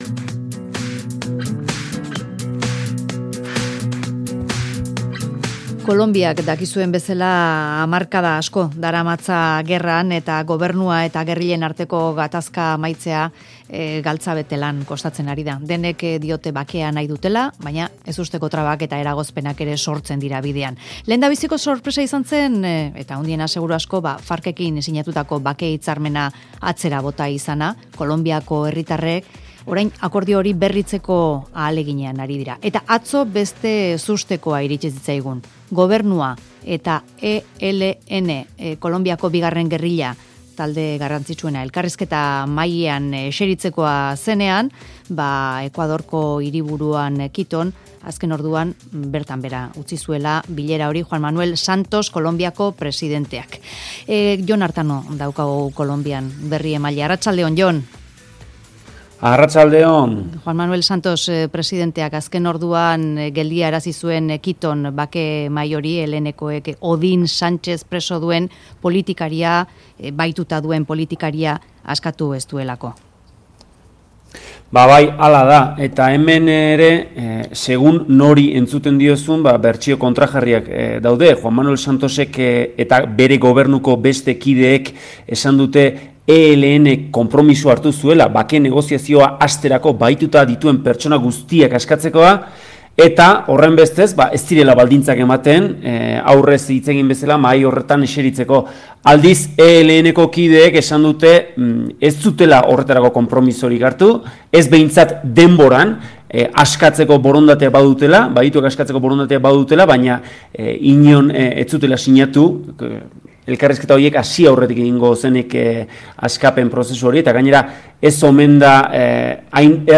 Bogotako korrespontsaliatik